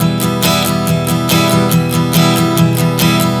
Strum 140 Em 02.wav